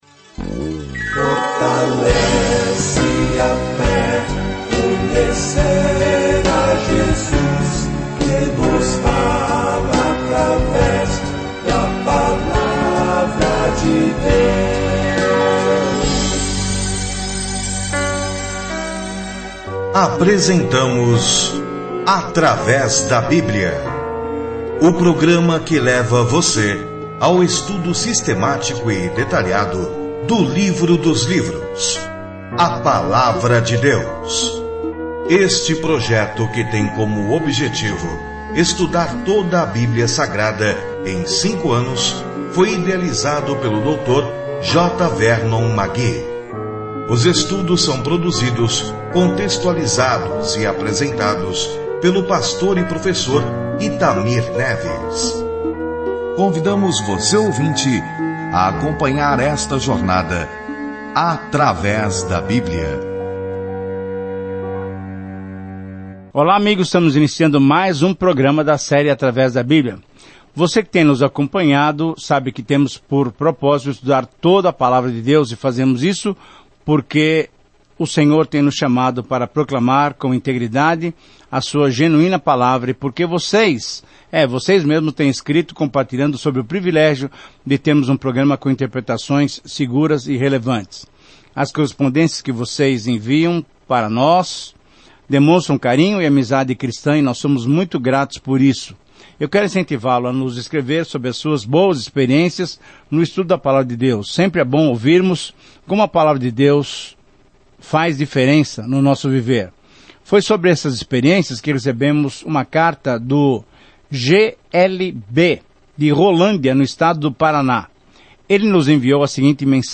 Scripture Job 23:1-17 Job 24:1-25 Day 16 Start this Plan Day 18 About this Plan Neste drama do céu e da terra, encontramos Jó, um homem bom, a quem Deus permitiu que Satanás atacasse; todo mundo tem tantas perguntas sobre por que coisas ruins acontecem. Viaje diariamente por Jó enquanto ouve o estudo em áudio e lê versículos selecionados da palavra de Deus.